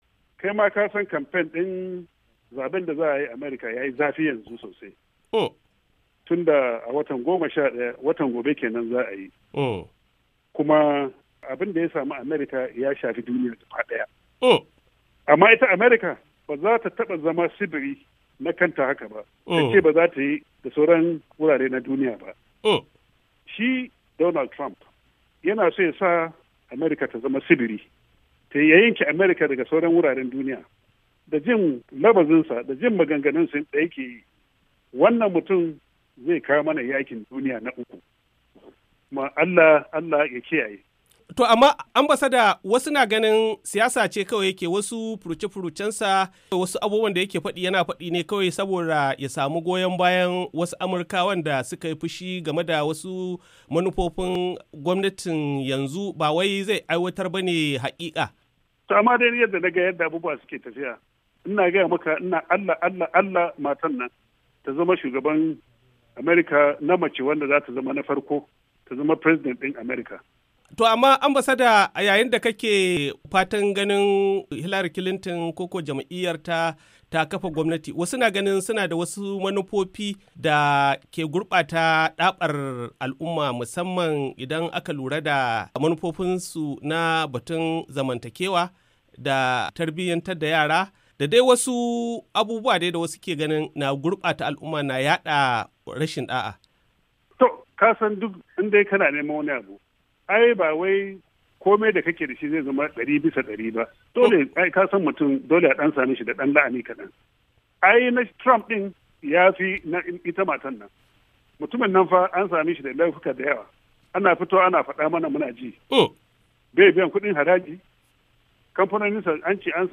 A zantawar da yayi da Muryar Amurka